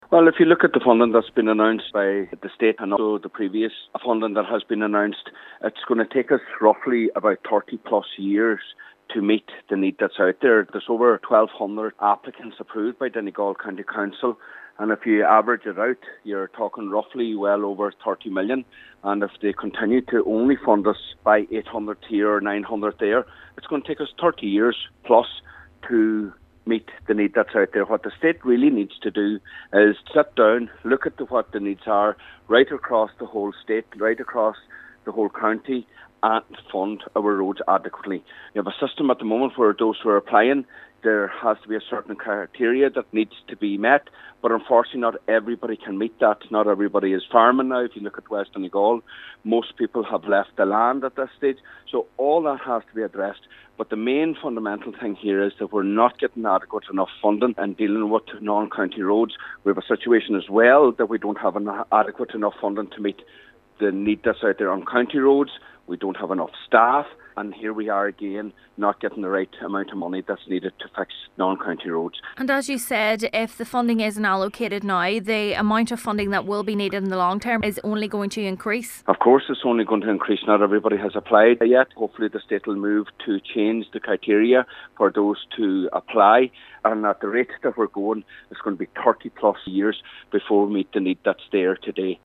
Cathaoirleach of the Glenties Municipal Distrcit, Councillor Micheal Cholm Mac Giolla Easbuig says unless the Government rolls out a significant funding package, it will take up to 30 years to meet the needs of the county: